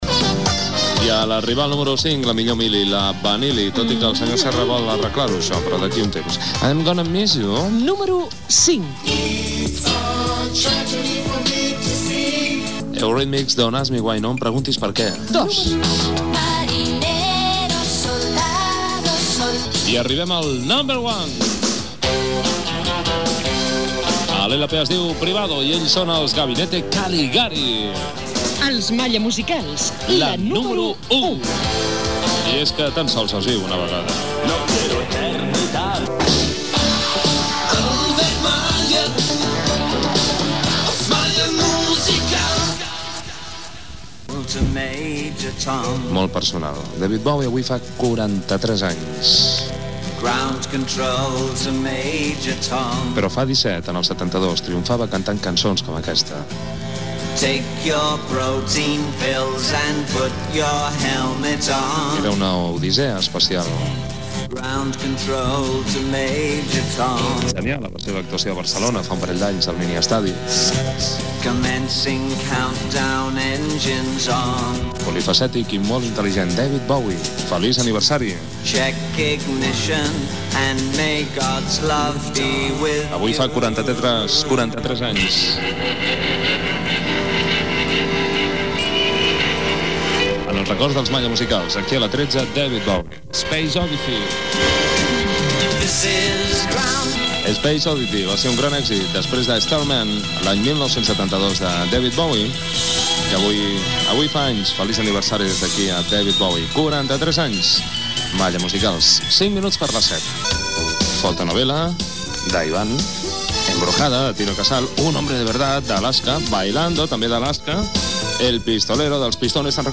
Cançons més altes a la llista, indicatiu del programa, presentació d'un tema musical de David Bowie el dia del seu aniversari, hora, etc.
Musical
FM